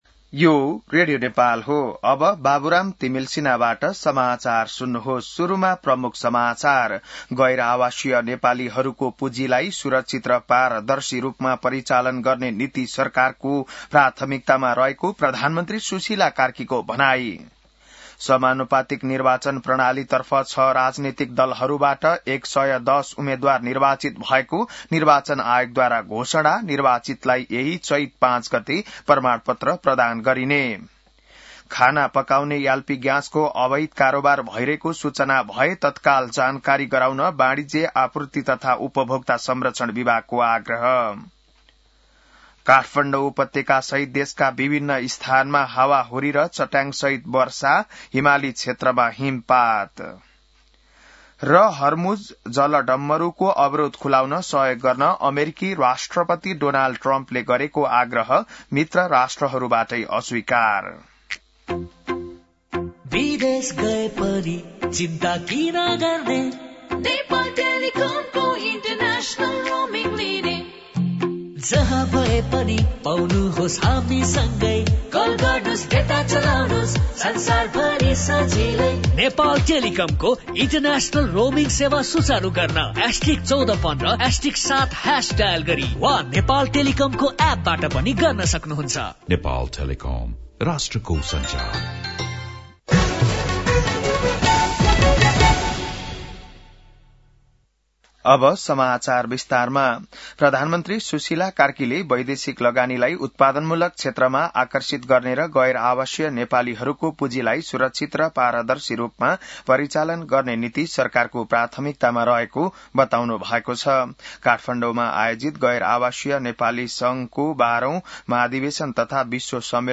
An online outlet of Nepal's national radio broadcaster
बिहान ७ बजेको नेपाली समाचार : ३ चैत , २०८२